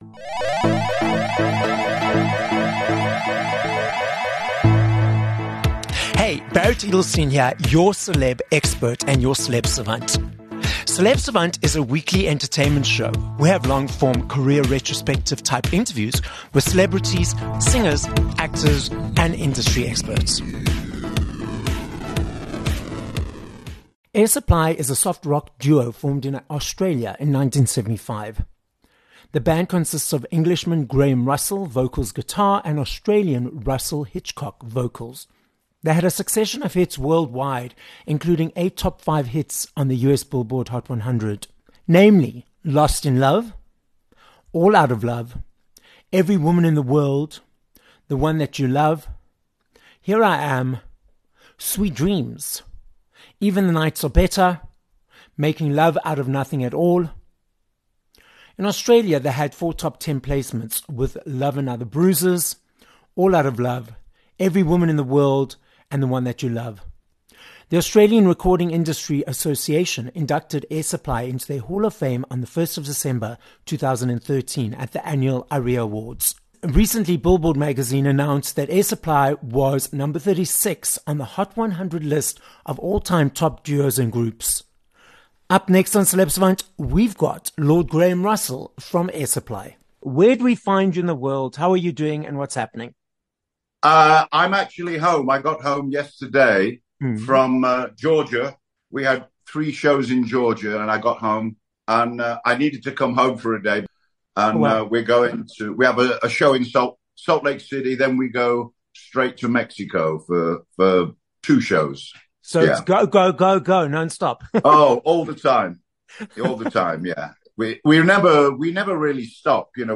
7 Aug Interview with Lord Graham Russell (Air Supply)